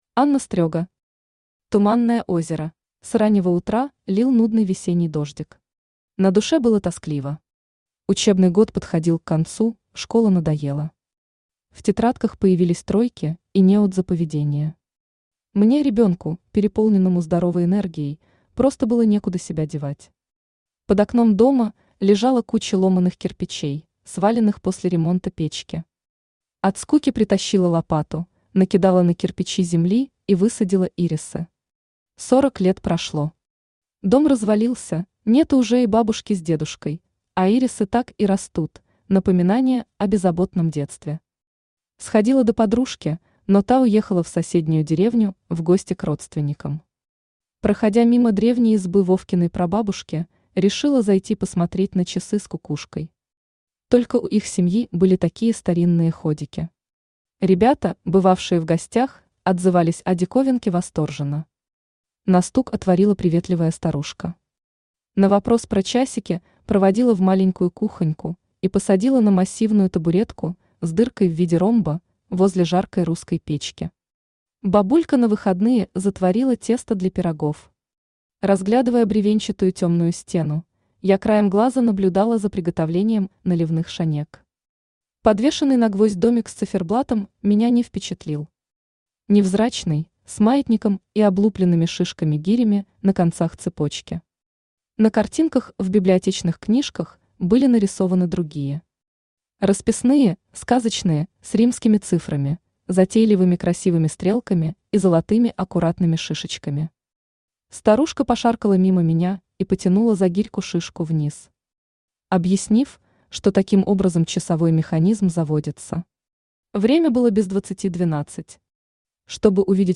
Аудиокнига Tуманное озеро | Библиотека аудиокниг
Aудиокнига Tуманное озеро Автор Aнна Николаевна Стрега Читает аудиокнигу Авточтец ЛитРес.